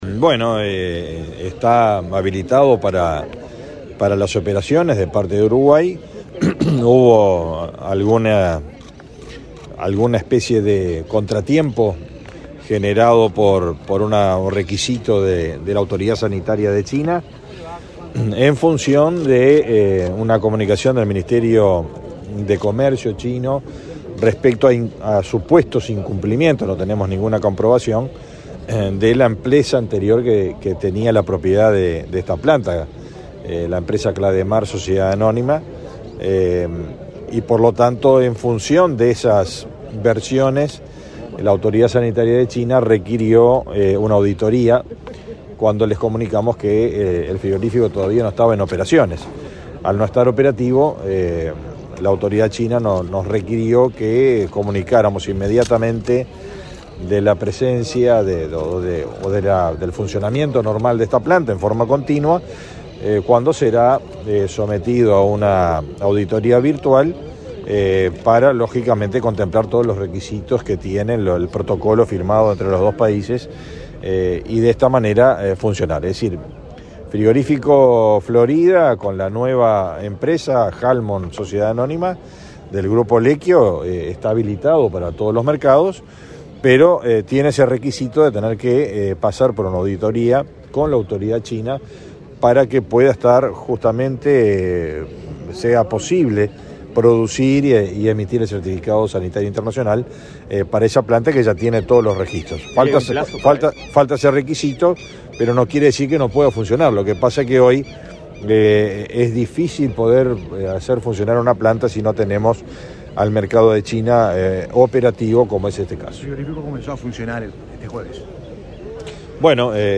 Declaraciones del ministro del MGAP, Fernando Mattos
El ministro Fernando Mattos informó sobre el tema en conferencia de prensa.